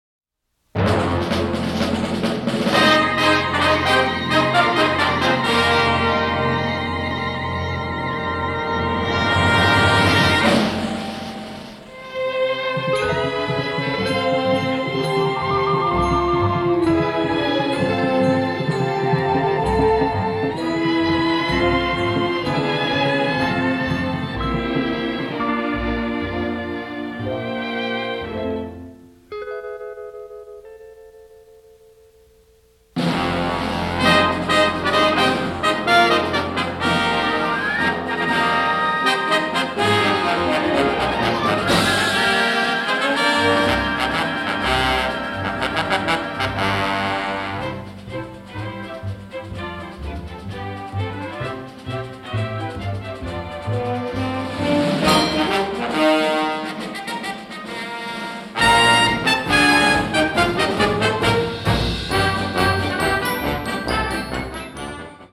rousingly patriotic score